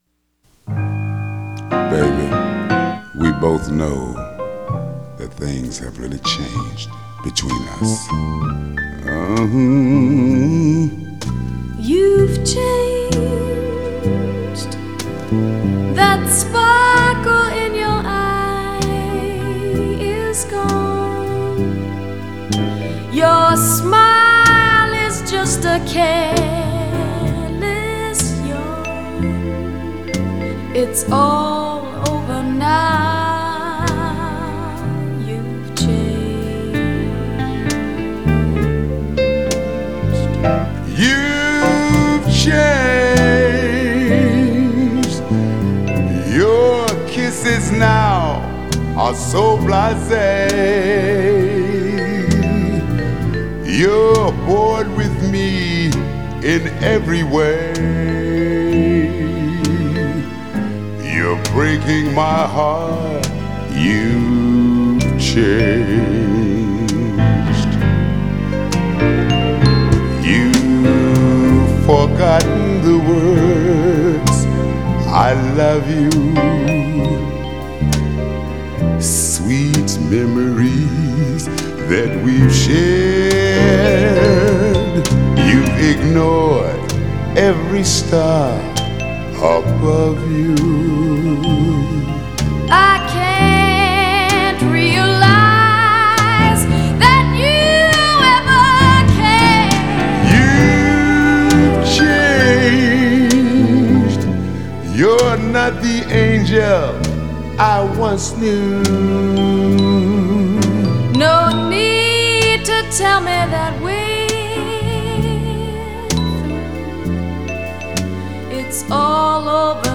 앨범은 재즈 , 블루스 및 소울 표준으로 구성되며 솔로와 듀엣이 혼합되어 있습니다.